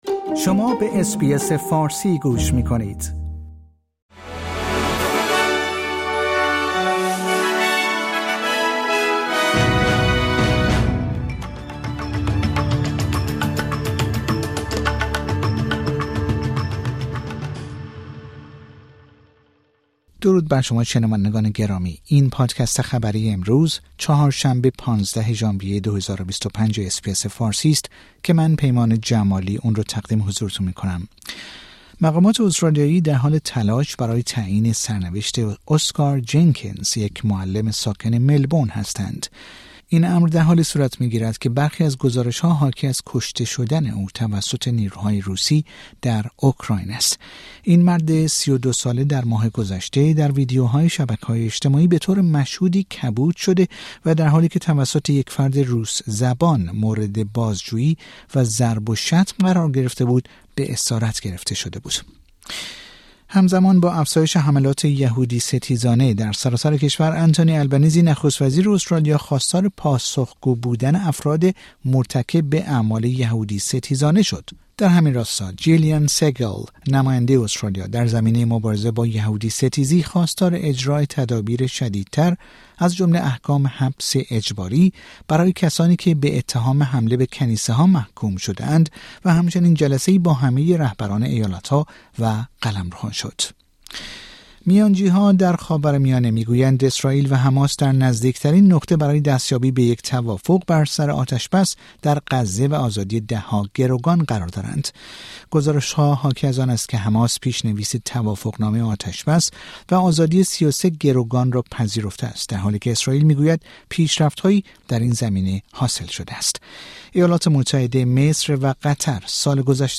در این پادکست خبری مهمترین اخبار استرالیا در روز چهارشنبه ۱۵ ژانویه ۲۰۲۵ ارائه شده است.